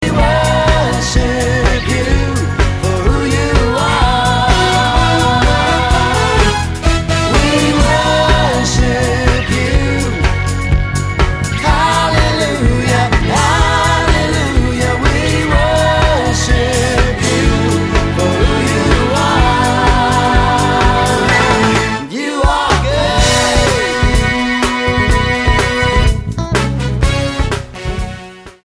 STYLE: Pop
This is a studio produced album